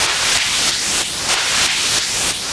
RI_RhythNoise_95-01.wav